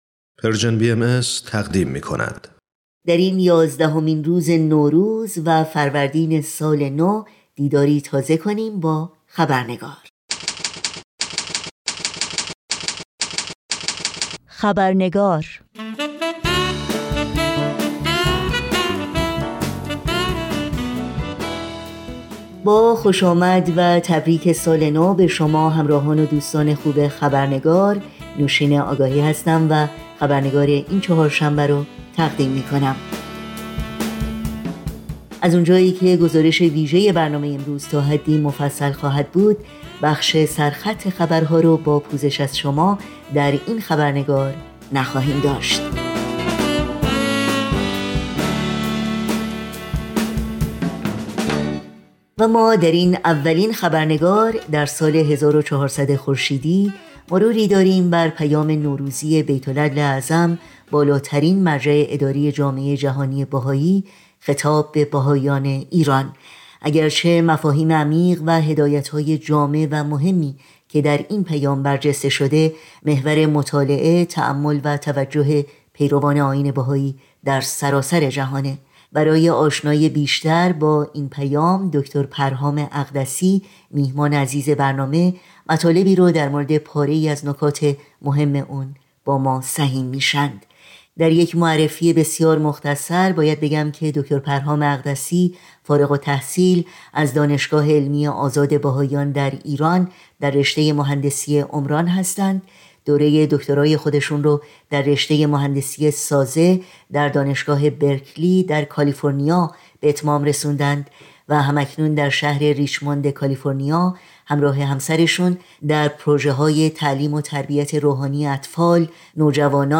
گفتگو با